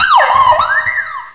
pokeemerald / sound / direct_sound_samples / cries / cinccino.aif